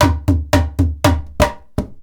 PERC 25.AI.wav